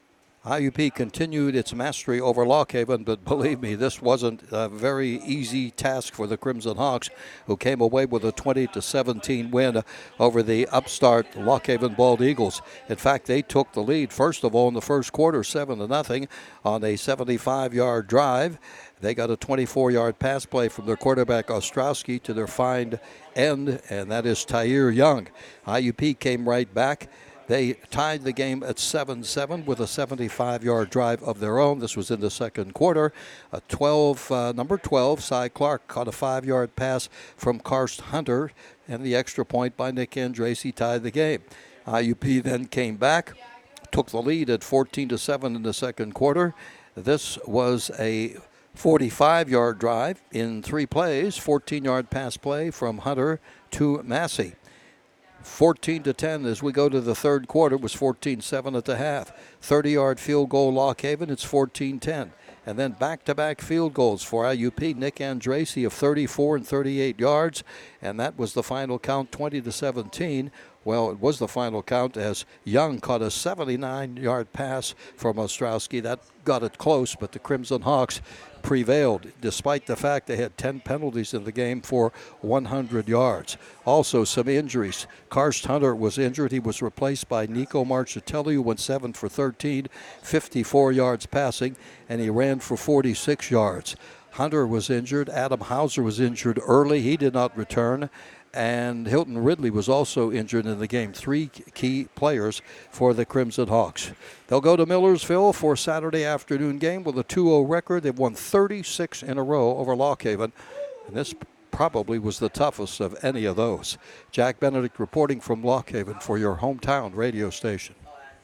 on U92.5 FM.